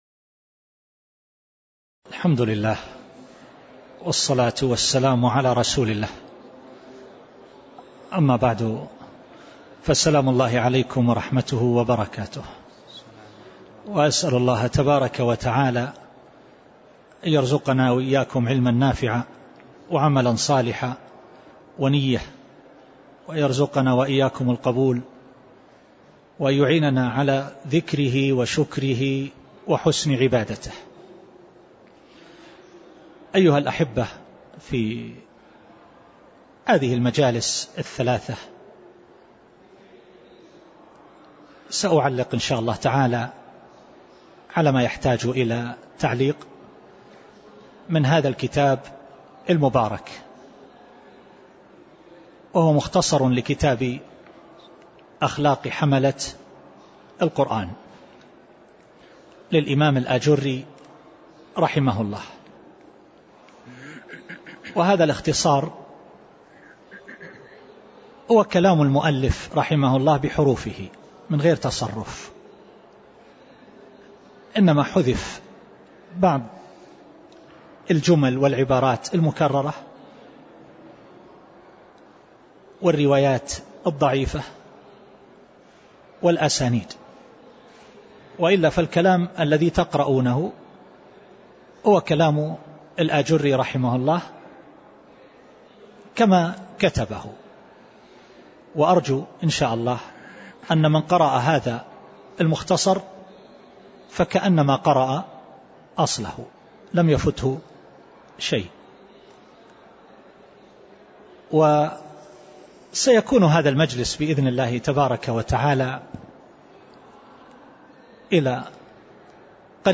تاريخ النشر ١٠ صفر ١٤٣٨ هـ المكان: المسجد النبوي الشيخ